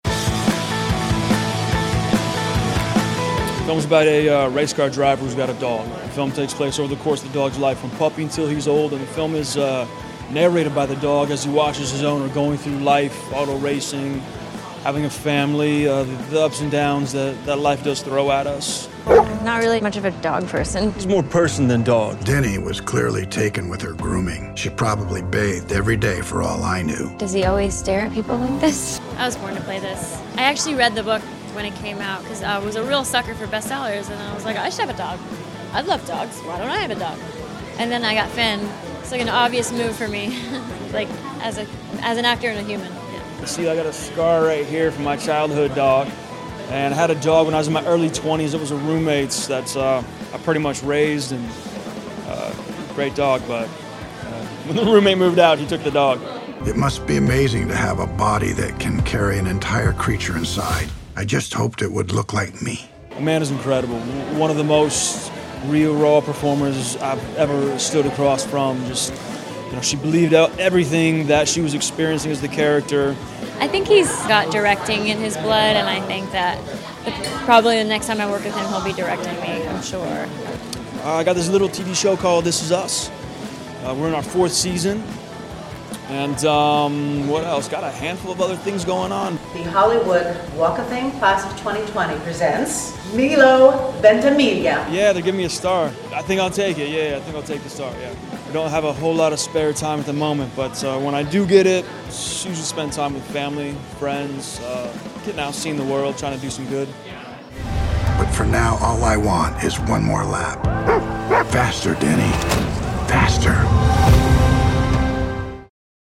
Milo Ventimiglia and Amanda Seyfried Share Chemistry On and Off the Screen at The Art of Racing in the Rain NYC Premiere
We got to chat with the co-stars about what it was like working with each other, their own pooches and what's next.